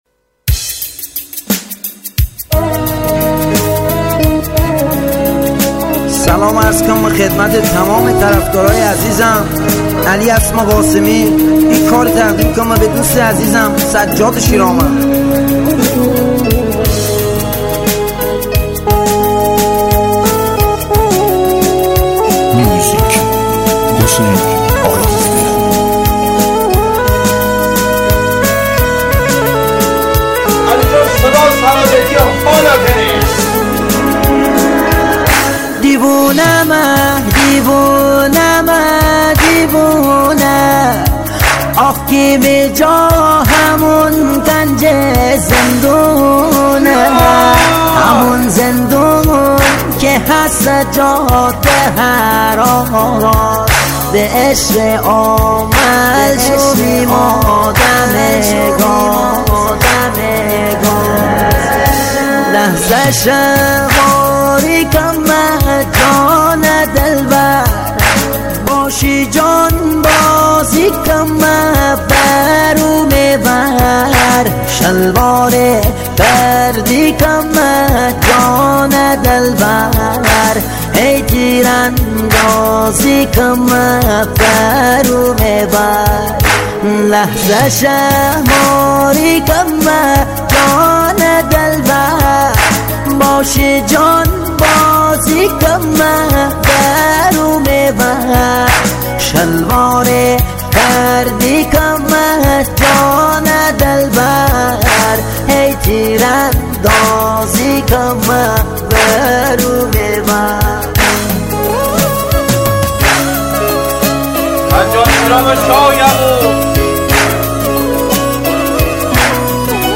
آهنگ مازندرانی جدید و زیبا
آهنگ شاد